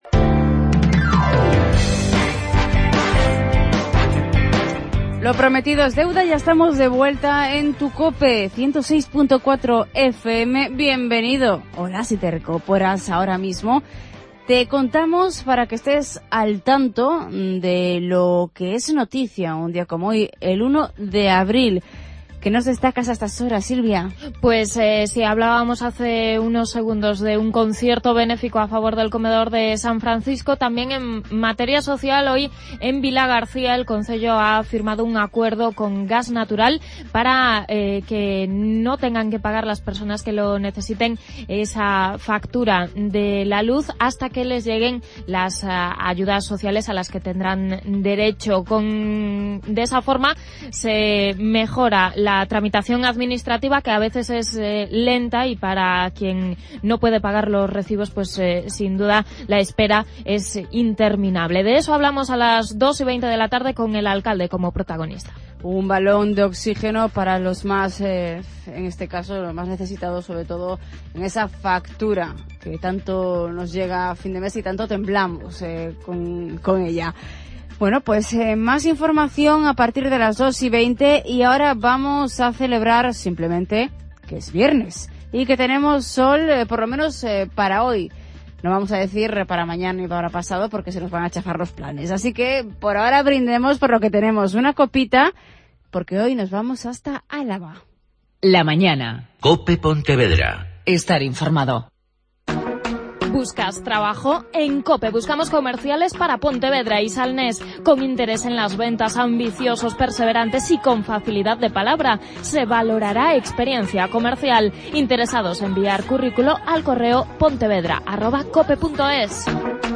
Psicóloga de niños.